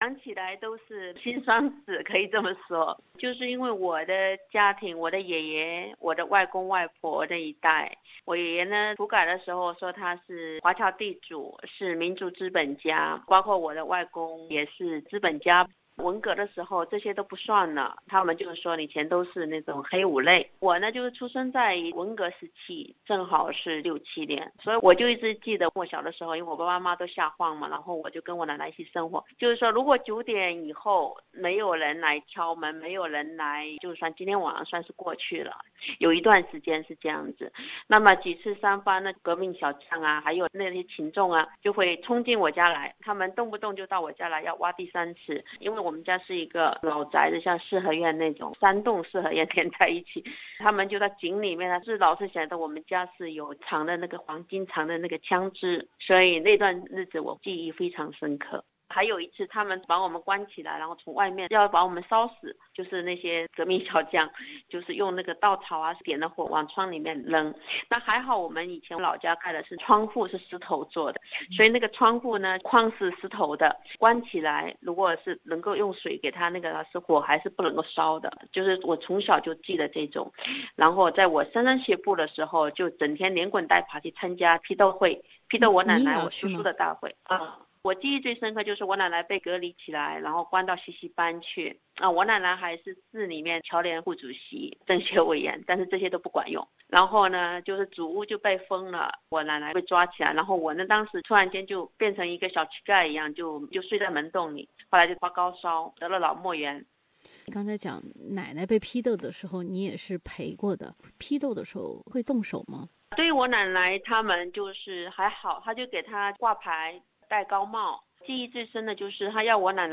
以上采访内容为嘉宾观点，不代表本台立场